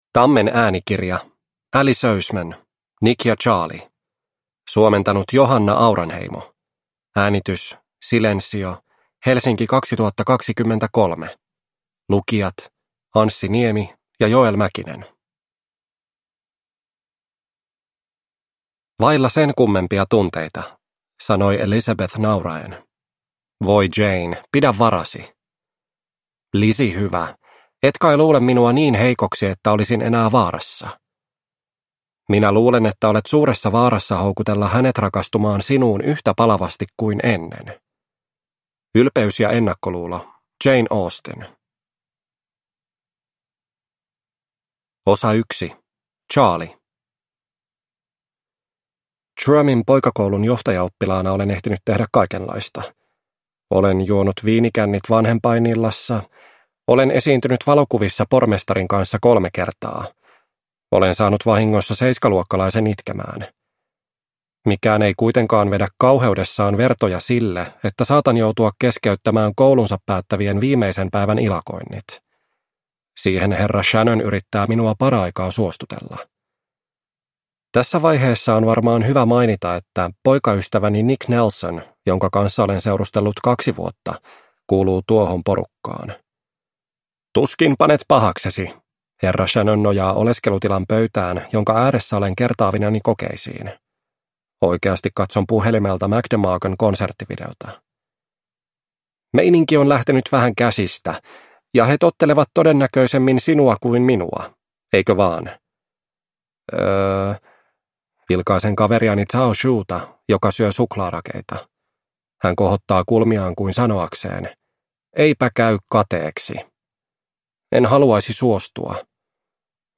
Nick ja Charlie – Ljudbok – Laddas ner